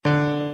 Piano Keys C Scale New